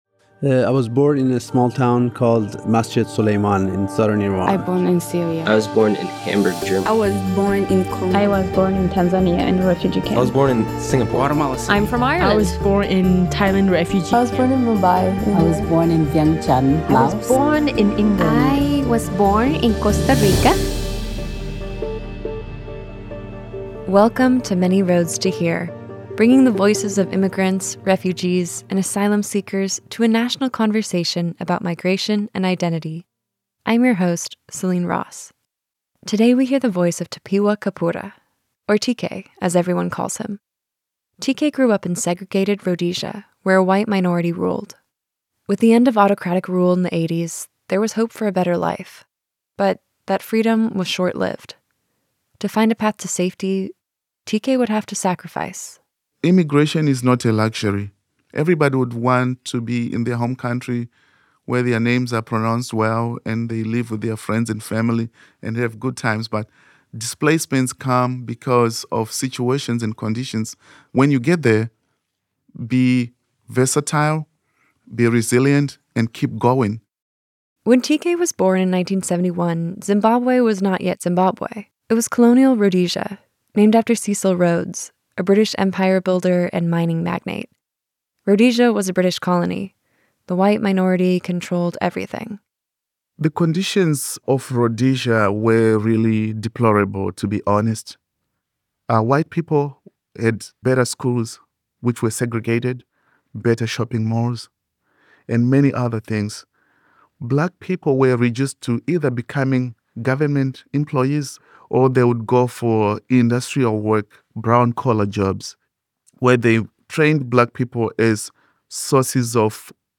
Thank you to St. Andrew Lutheran Church in Beaverton for the use of their space to record the interview.